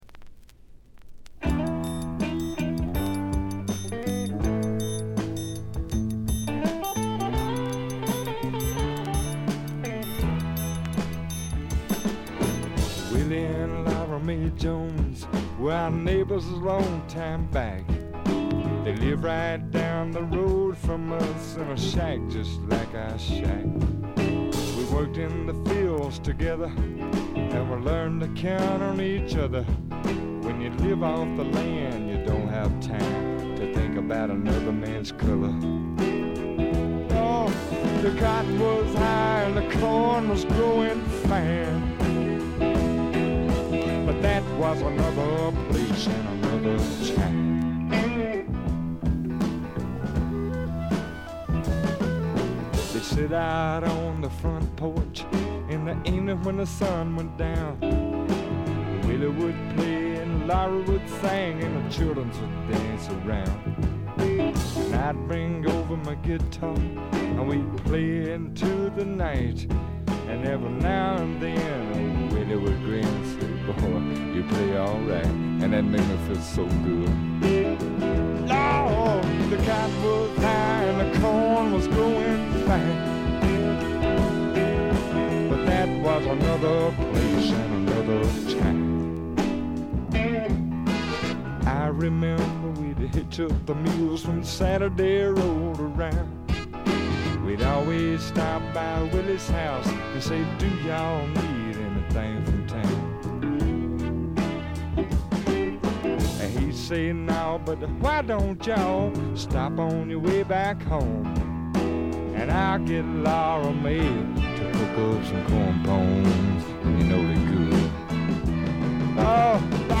チリプチ、プツ音多め大きめ。周回気味の箇所もあり。
スワンプ基本！
試聴曲は現品からの取り込み音源です。
Piano, Organ
Guitar, Harmonica